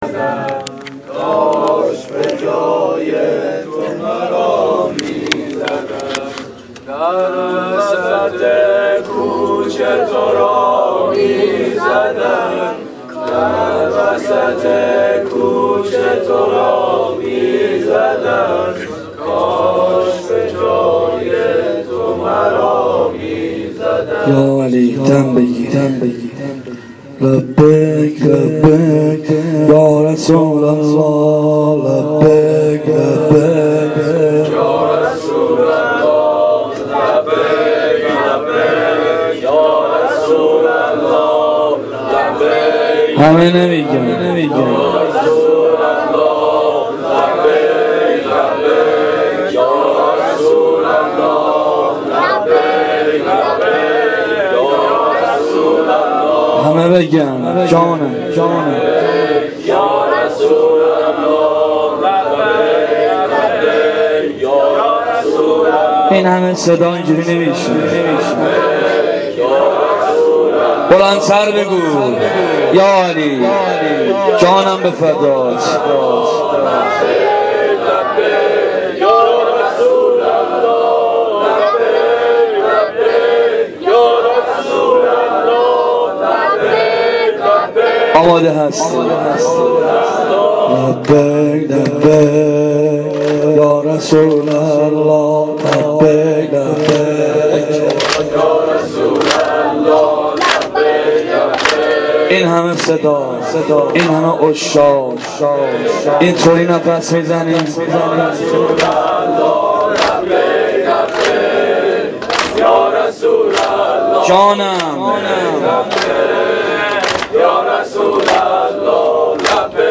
زمینه-حاجی5.mp3